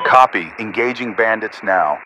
Added .ogg files for new radio messages
Radio-pilotWingmanEngageAir1.ogg